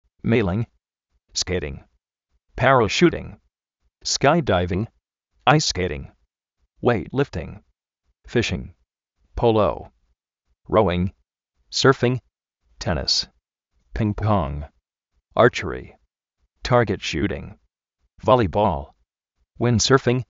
séiling
skéiting
parashútin, skái-dáivin
uéit lífting
róuing
tárguet shú:ring
vólibol
uínd-sérfing